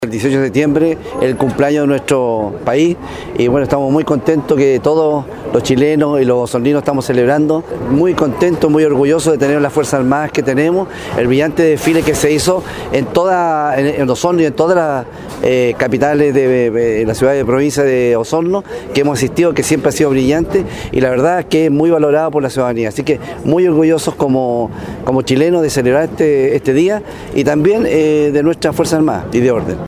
Por su parte el Gobernado de Osorno, Daniel Lilayú agradeció al personal militar que se desplegó en cada comuna y localidad que lo requirió en estas fiestas patrias.